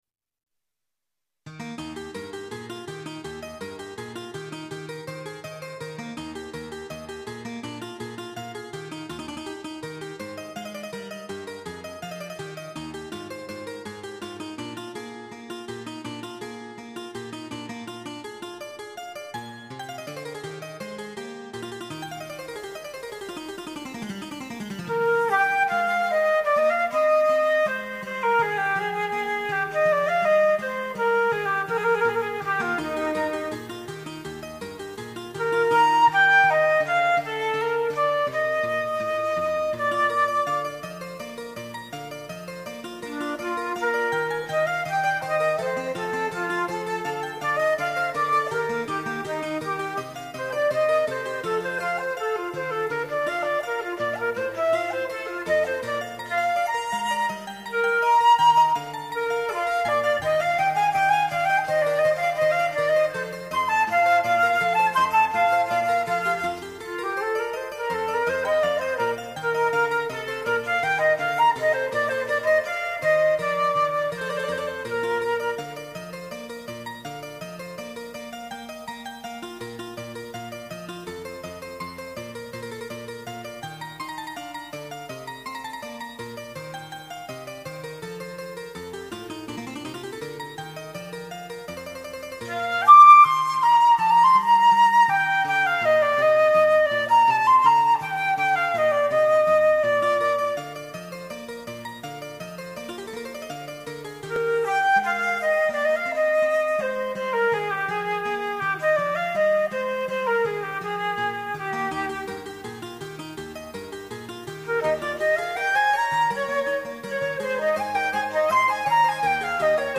昔公開した私の演奏です。
Flute Sonata Es dur BWV1031 I (J. S. Bach)